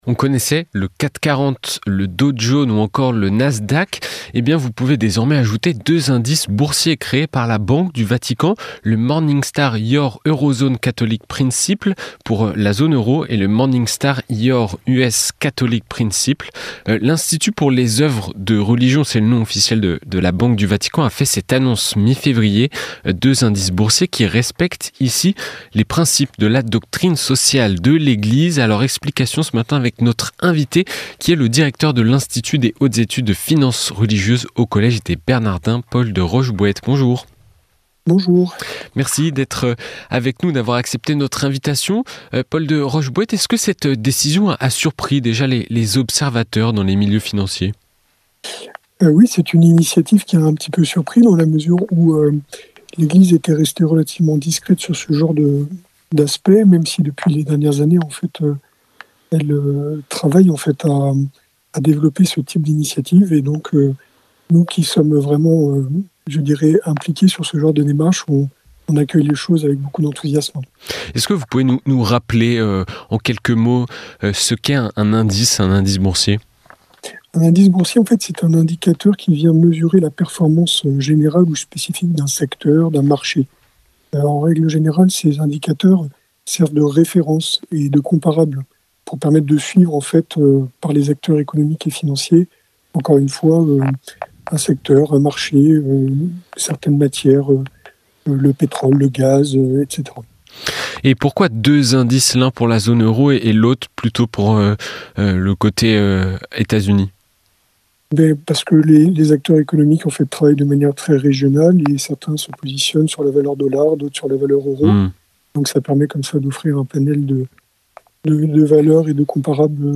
Accueil \ Emissions \ Information \ Régionale \ Le grand entretien \ Finance éthique : pourquoi la banque du Vatican a-t-elle créé deux indices (…)